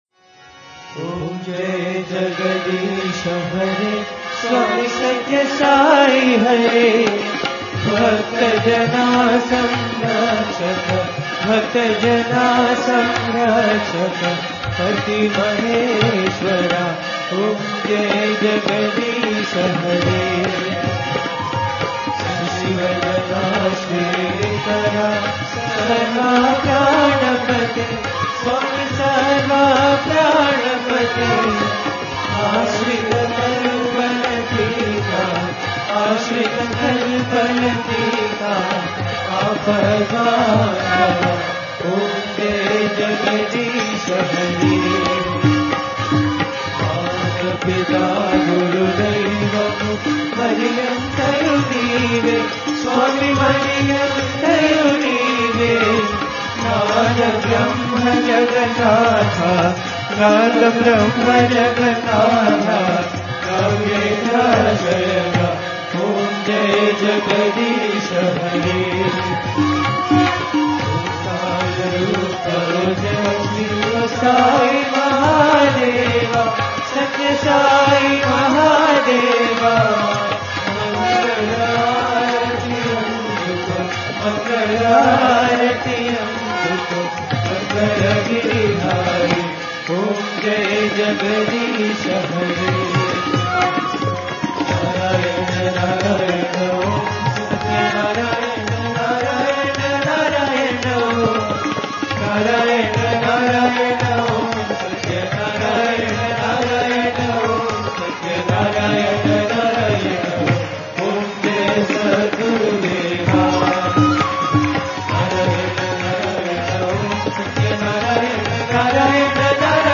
AARTI – Sri Sathya Sai Baba Center Sacramento
Night {Shej} Aarti